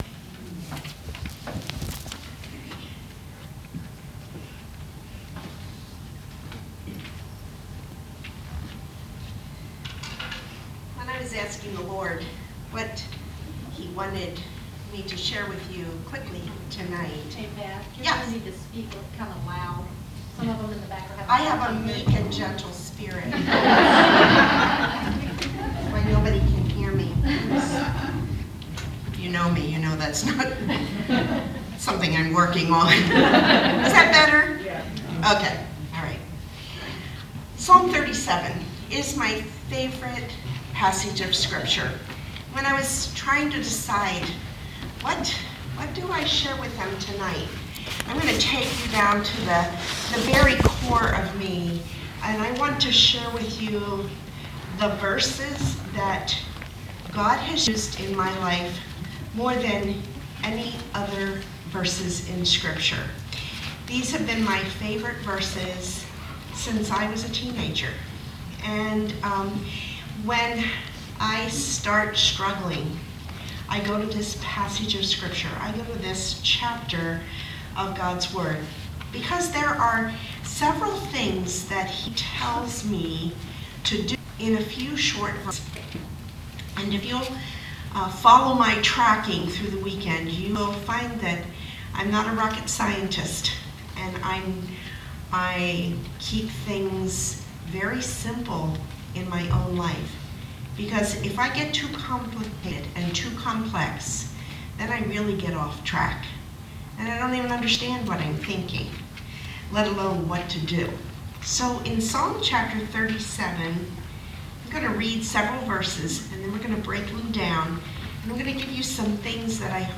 Audio Sessions of Ladies Conferences at Westside Baptist Church
Devotional talk